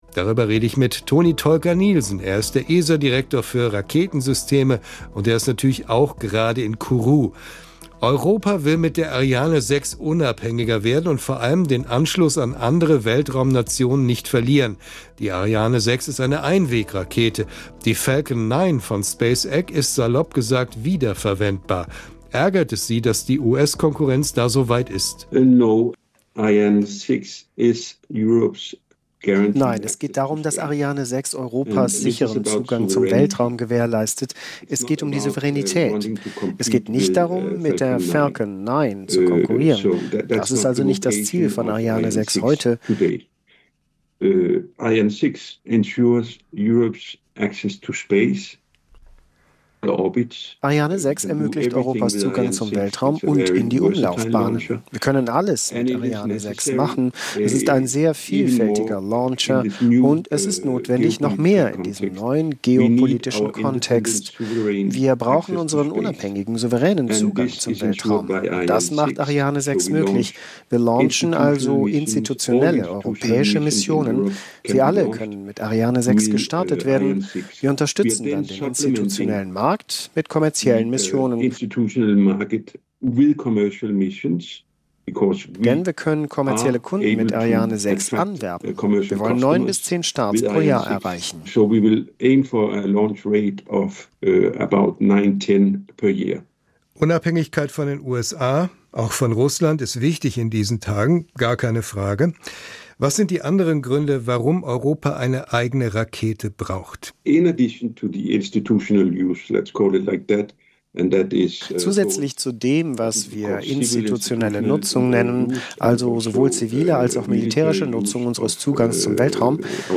Ein Gespräch über Kooperation und Konkurrenz in der Raumfahrt – und warum die Europäer nicht unbedingt neidisch auf die USA sein müssen, nur weil deren Falcon 9 Raketen schon einen Entwicklungsschritt weiter sind.